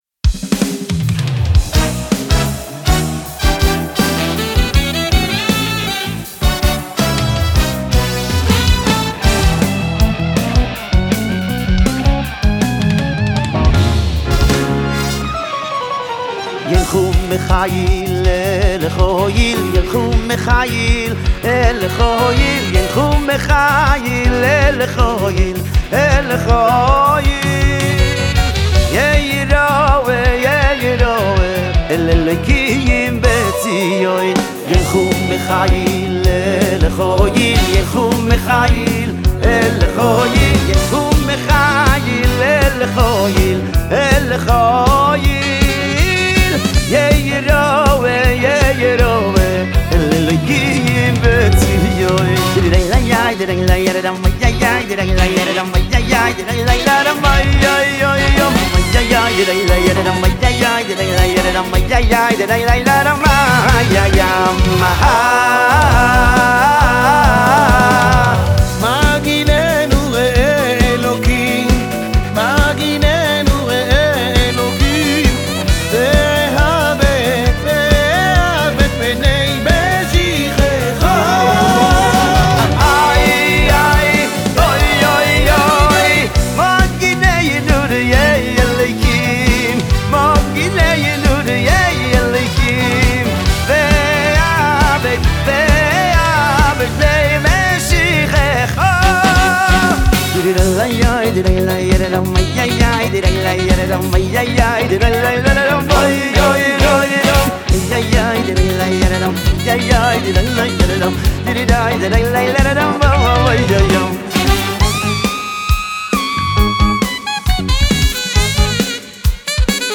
הזמר החסידי הוותיק
קלידים והפקה מוזיקלית